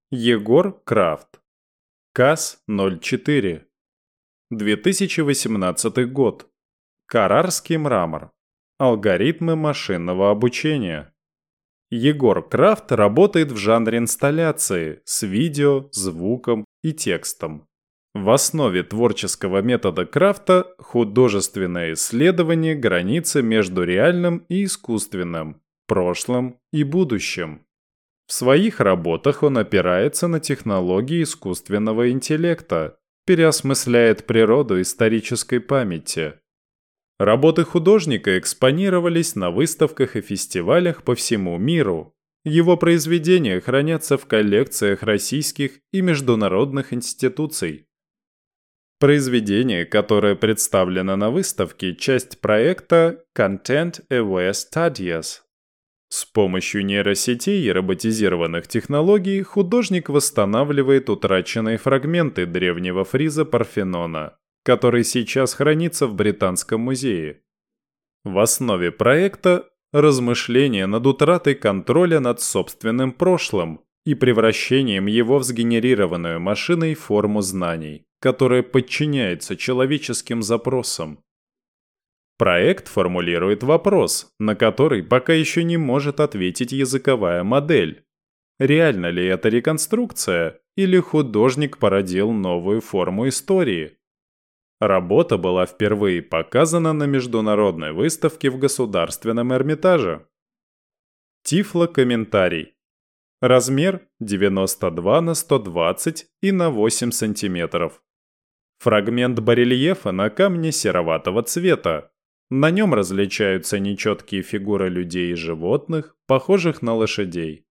Тифлокомментарий к картине Егора Крафта "Кас 04"